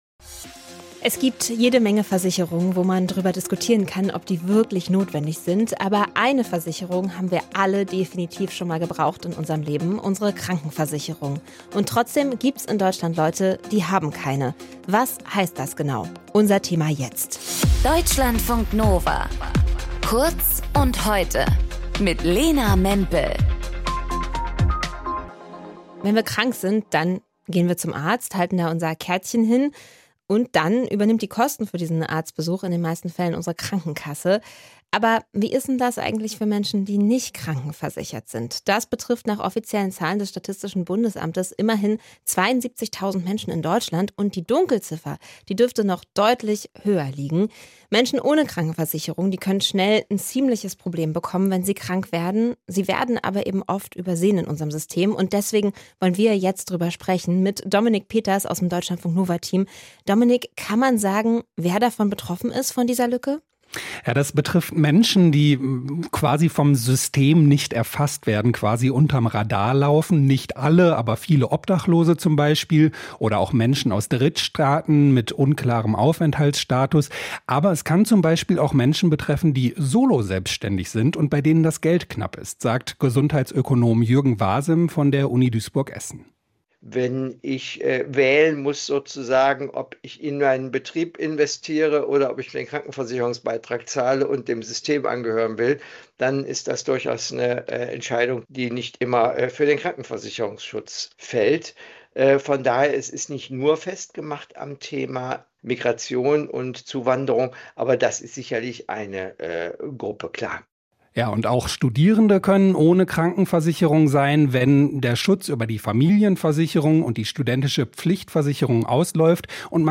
Moderation:
Gesprächspartner: